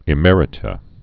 (ĭ-mĕrĭ-tə)